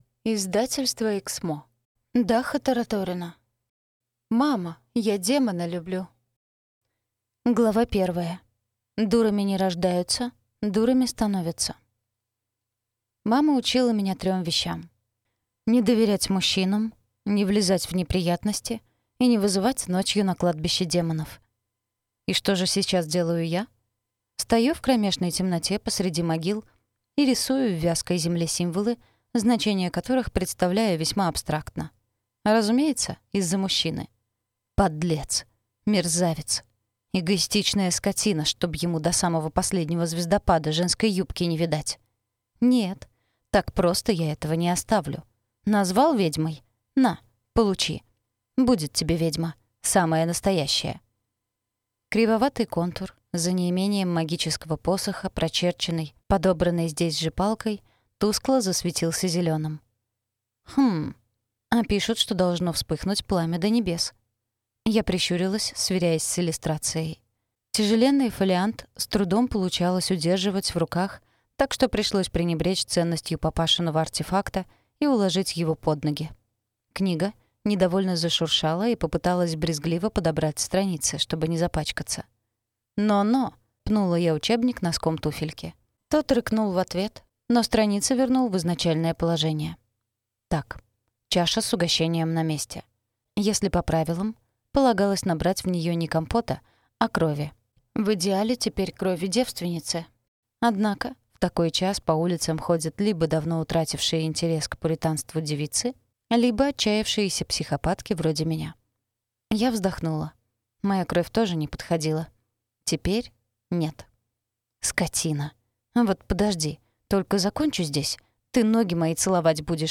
Aудиокнига Мама, я демона люблю!